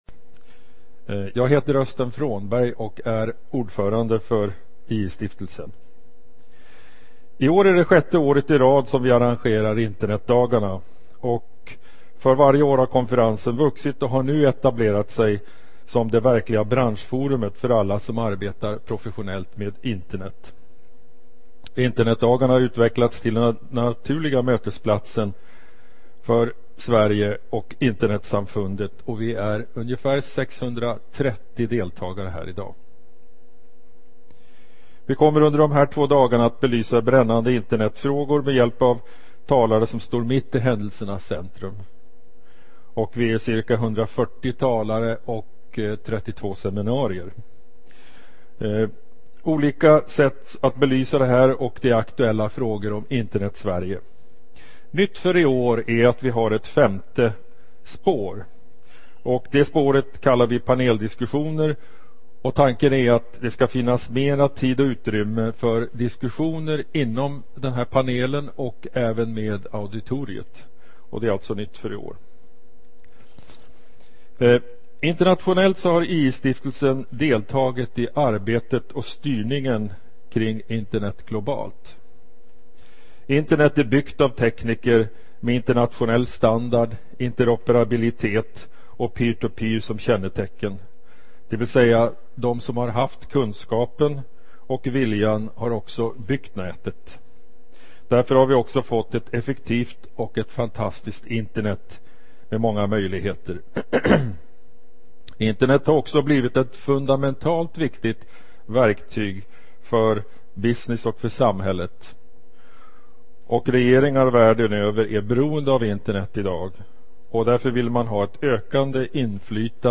Under inledningsseminariet belyses dessa aspekter.